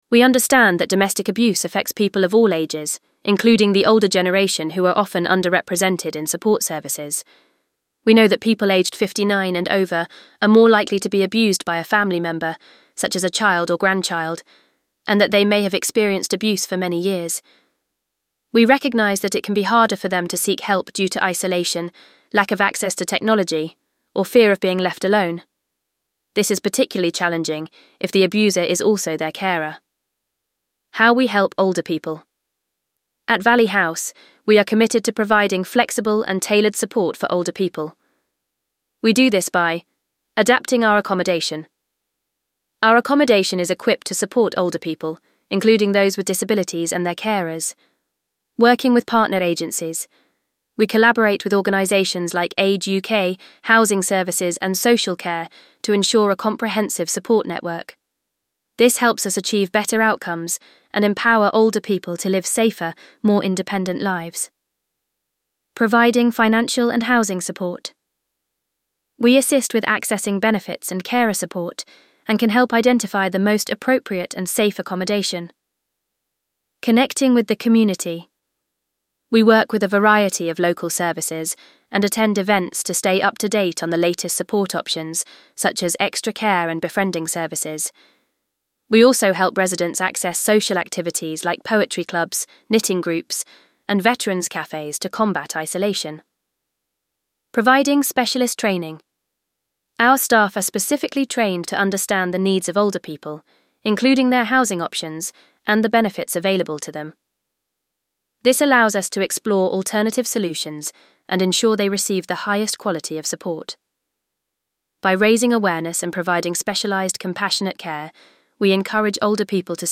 VOICEOVER-Older-People.mp3